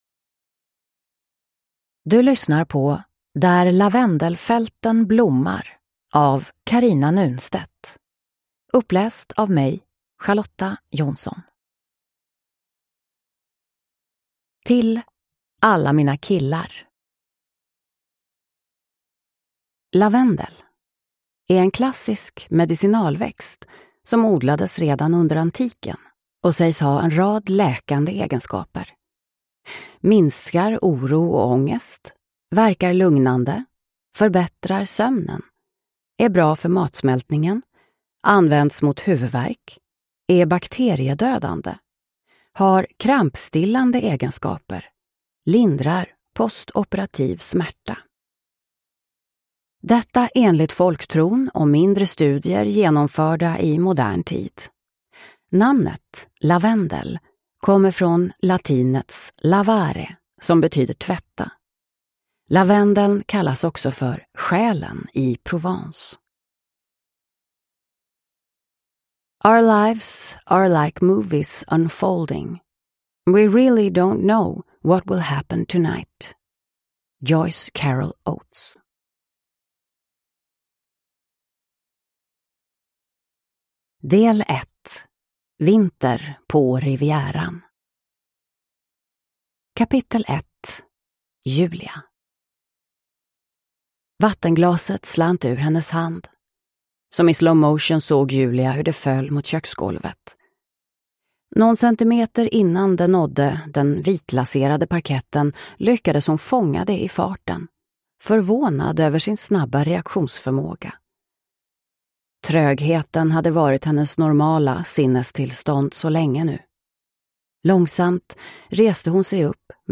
Där lavendelfälten blommar – Ljudbok